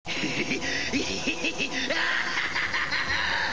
I have this one sound clip that i listen to when i need to think...screw them...lol, anyways, its Vejiita's insane laugh....